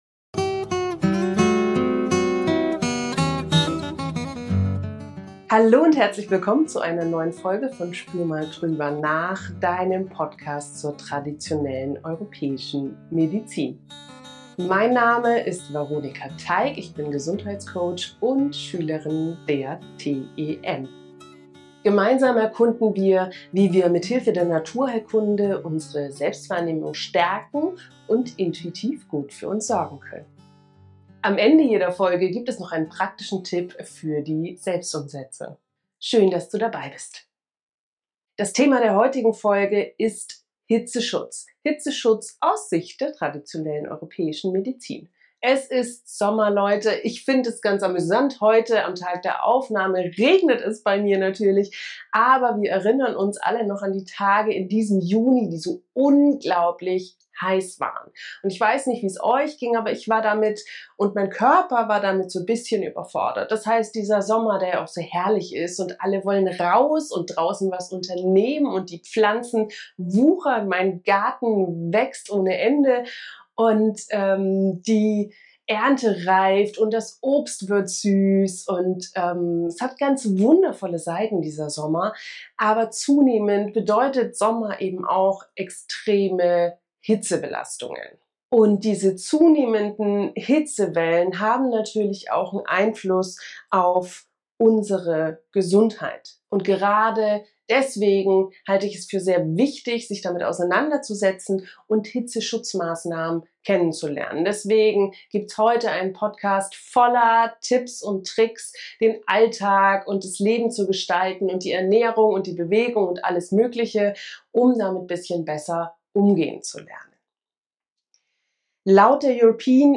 Zur Abwechslung gibt es mit dieser Folge mal wieder alle möglichen Probleme: Die Kamera fiel aus, dann fiel das Mikro aus und der Fleck auf dem Shirt war wohl auch nicht nur Wasser.